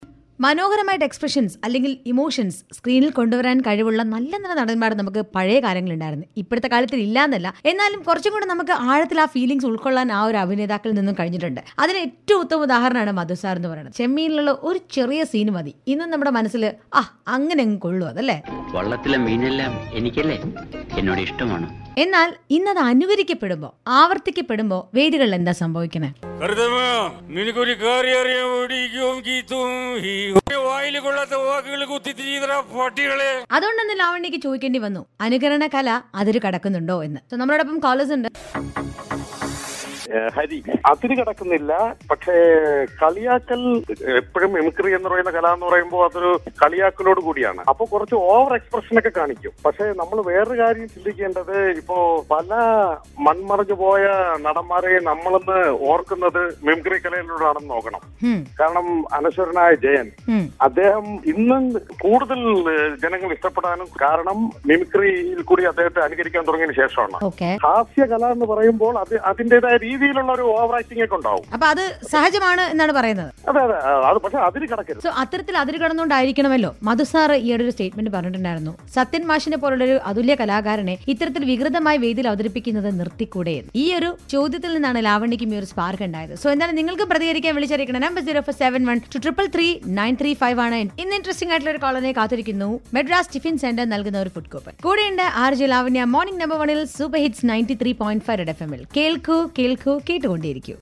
Mimicry